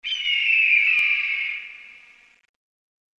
Dzwonki na telefon Orzeł
Kategorie Zwierzęta